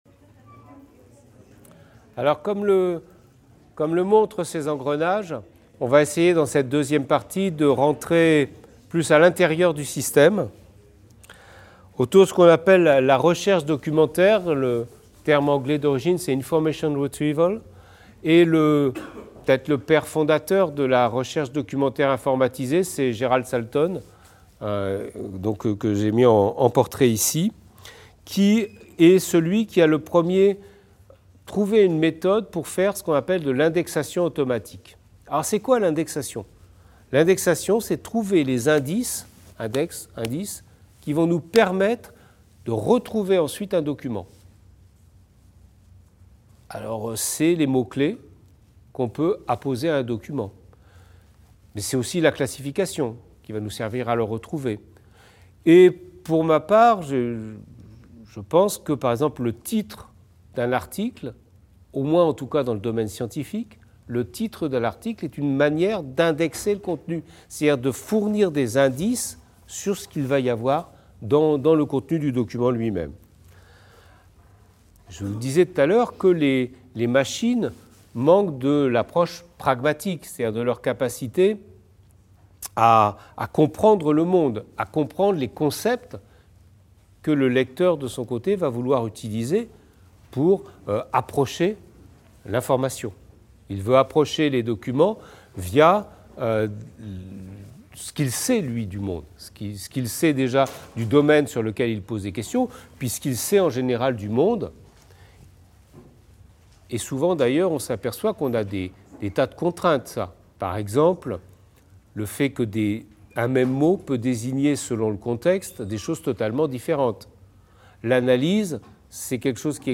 Cours commun de culture numérique 2016-2017